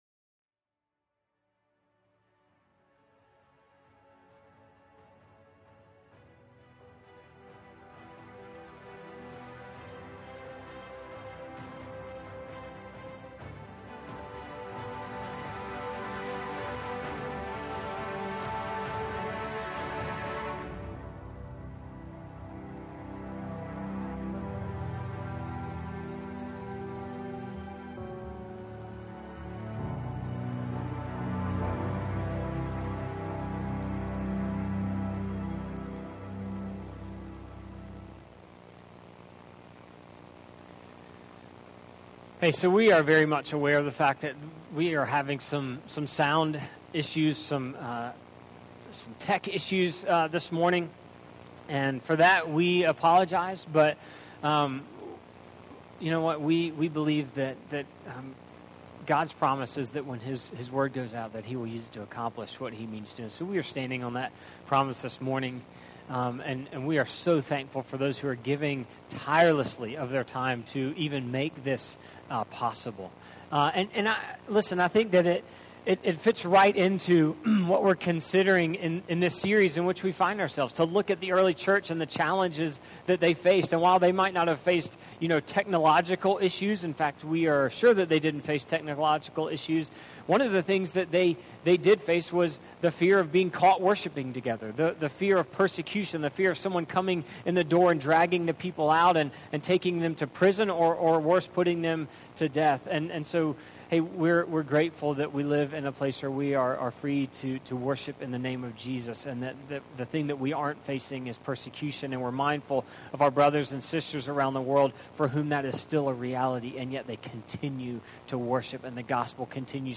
september-6-2020-reimagining-church-worship.mp3